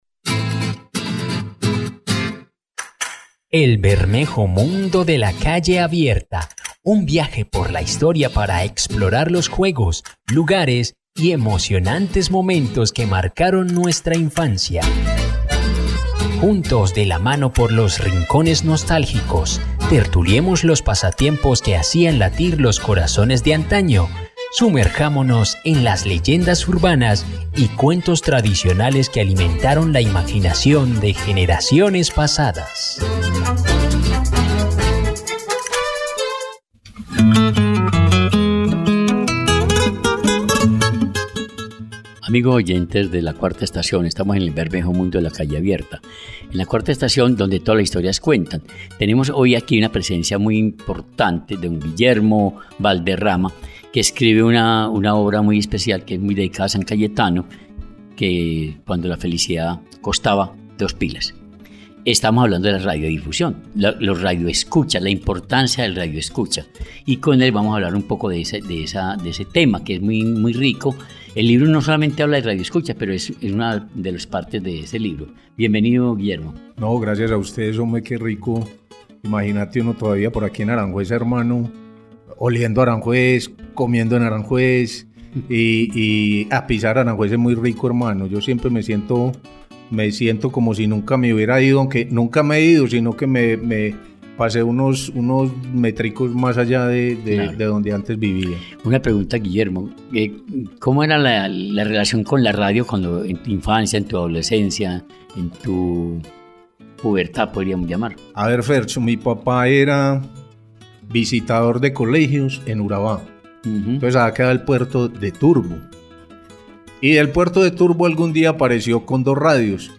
🤩Además, tendrás la oportunidad de escuchar los testimonios de los habitantes de la Comuna 4 – Aranjuez sobre su relación con la radio.🎙La Cuarta Estación, donde ¡Todas Las Historias Cuentan! y las Voces de Aranjuez Resuenan.